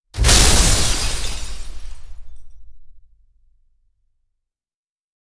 Звуки взрывов разные
Грохот взрыва дома с выбитыми стеклами
grokhot-vzryva-doma-s-vybitymi-steklami.mp3